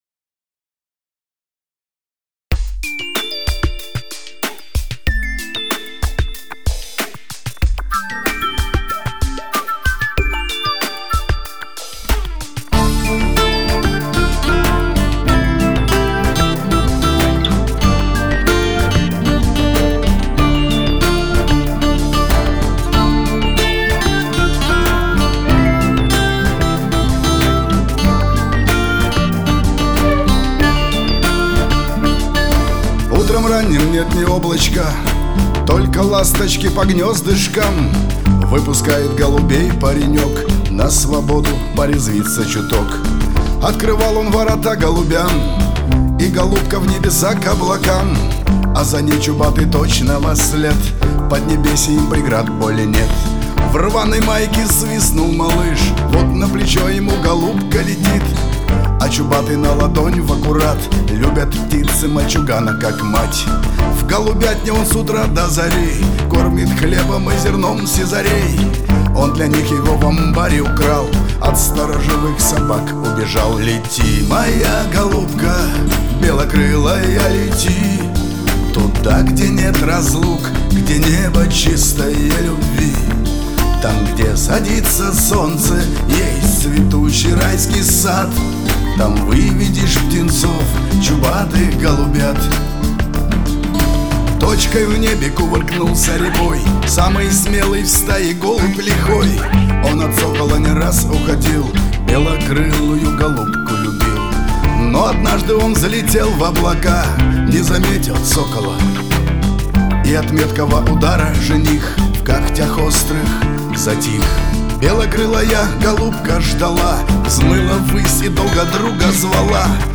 Файл в обменнике2 Myзыкa->Шансон, Барды
Стиль: Шансон